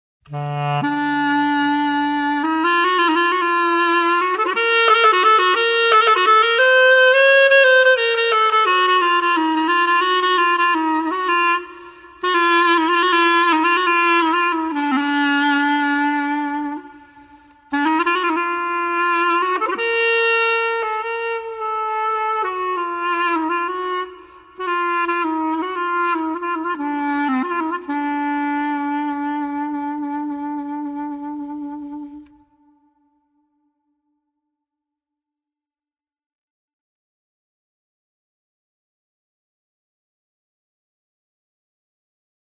Пример мелодии для кларнета